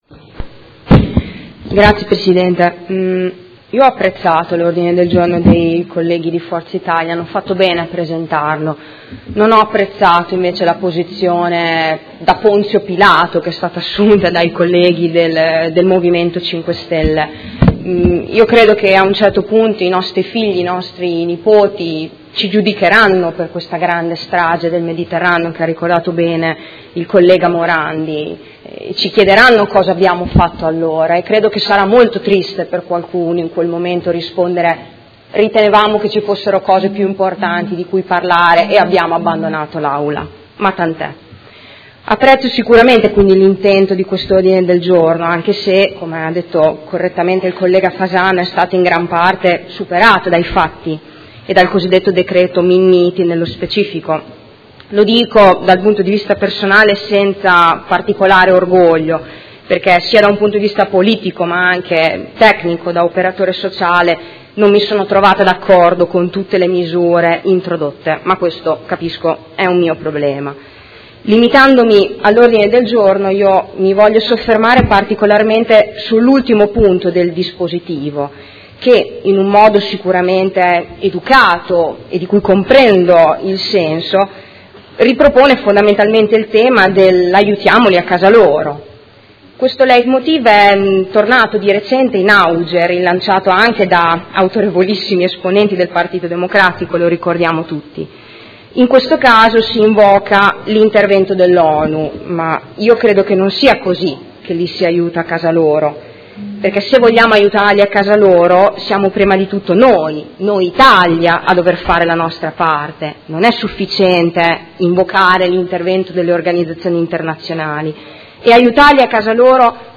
Seduta del 9/11/2017. Dibattito su Ordine del Giorno del Gruppo Forza Italia avente per oggetto: L’aumento costante degli arrivi di migranti richiede politiche chiare e decisive per limitare e regolamentare il flusso e interrompere la catena sempre più lunga dei morti in mare